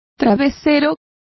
Complete with pronunciation of the translation of bolsters.